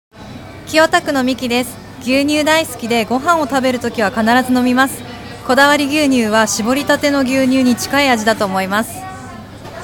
試飲いただいたお客様の生の声
リンクをクリックするとこだわり牛乳を試飲いただいた皆様からの感想を聞くことができます。
4月8日（火）15:00～18:00　ビッグハウス 新川店
お客様の声6